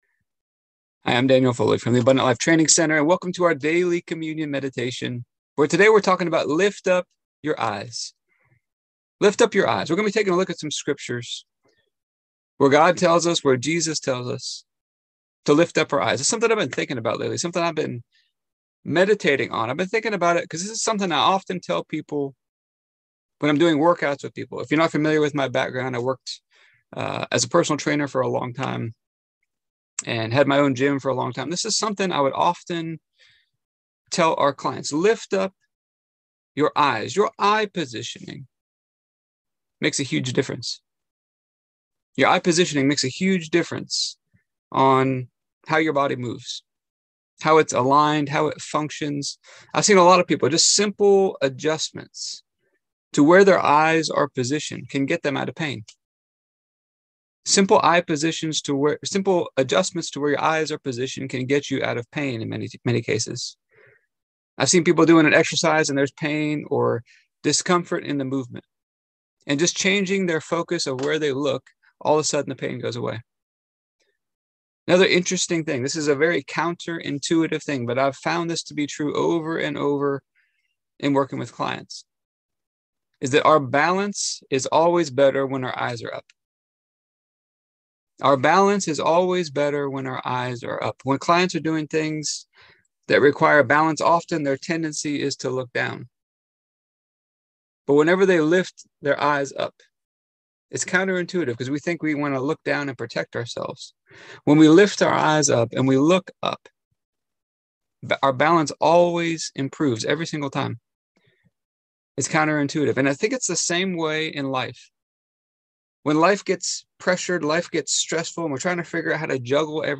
Here is today's communion meditation.